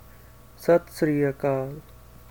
Sat Śri Akāl (Gurmukhi: ਸਤਿ ਸ੍ਰੀ ਅਕਾਲ, pronounced [sət sɾiː əkɑːl]
SatSriAkaal_greeting.ogg.mp3